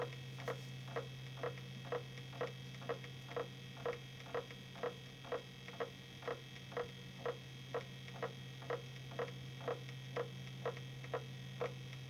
KlackKlackKlack macht die Seagate Platte
Ich habe ja Zweifel, dass exakt identische Fehler (die HDDs klackern echt im Gleichtakt), von HDDs aus zwei Quellen, zwei Chargen realistisch Hardware sind.
Das sind drei HDDs im Gleichschlag. Eine HDD allein bekomme ich nicht gescheit aufgenommen.